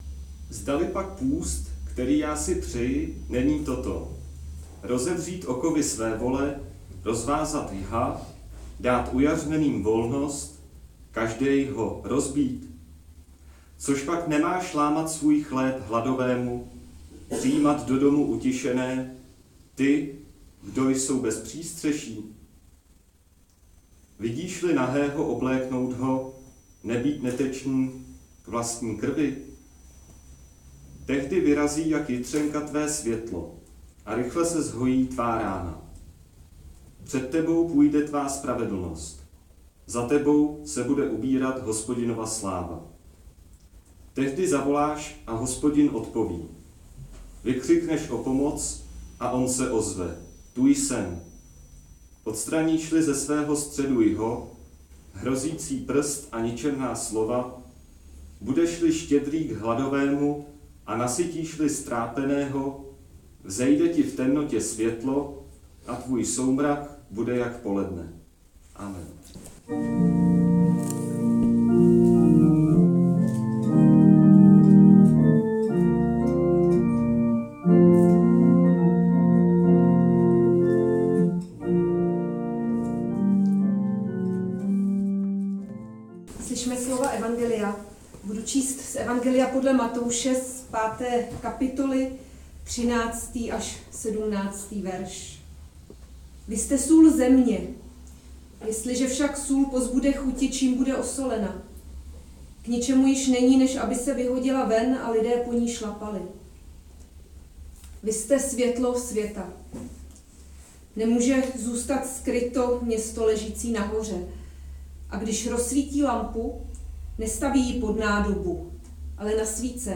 záznam kázání: Matoušovo evangelium 5, 13 – 17